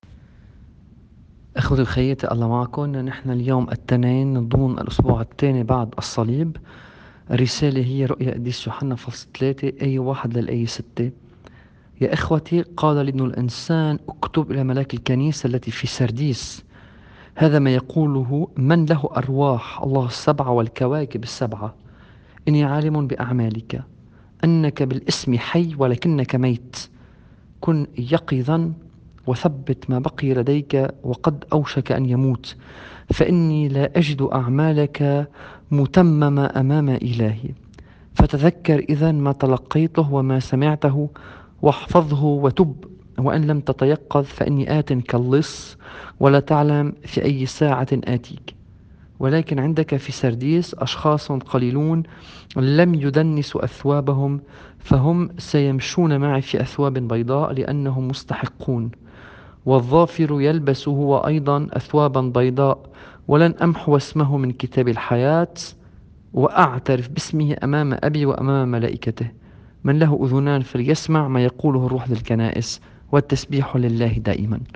الرسالة بحسب التقويم الماروني :